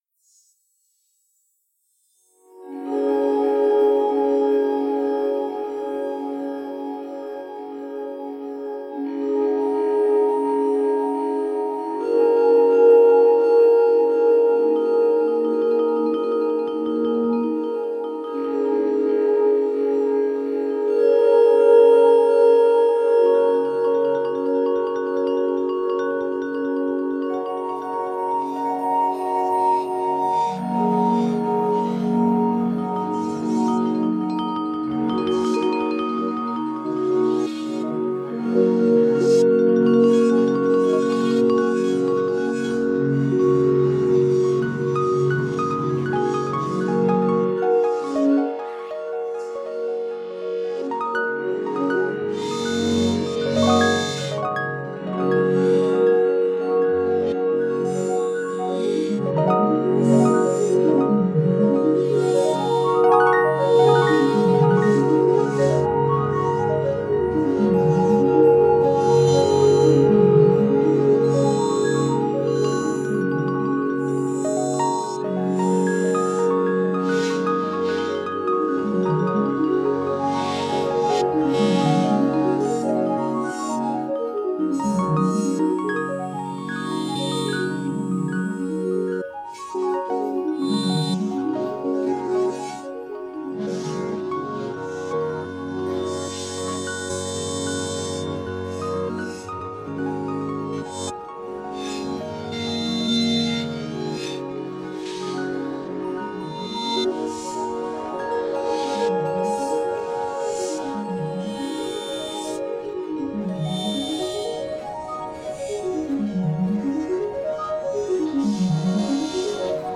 Electronix Ambient